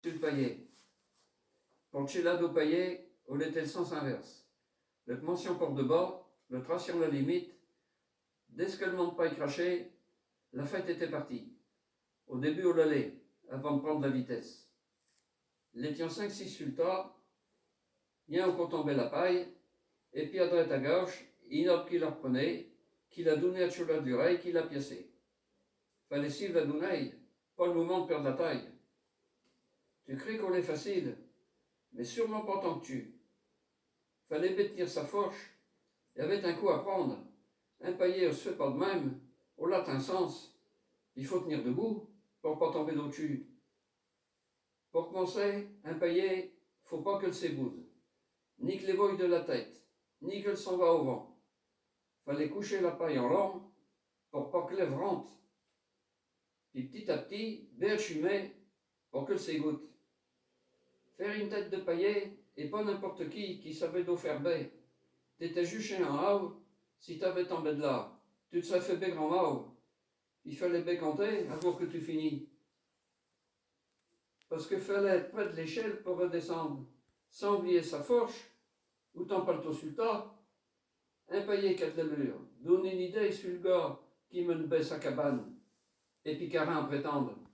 Genre poésie
Poésies en patois